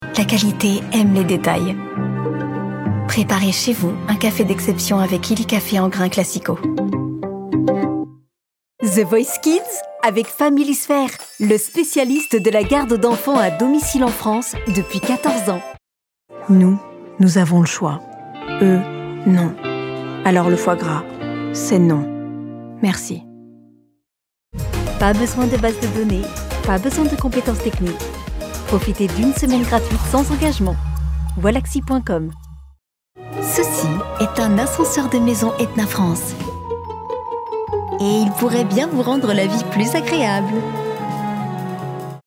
Comédienne voix off bilingue: Un sourire dans une voix…
Ma voix peut être rassurante, convaincante, chaleureuse, sensuelle et séduisante, mais aussi pétillante, enjouée et avec une tonalité enfantine.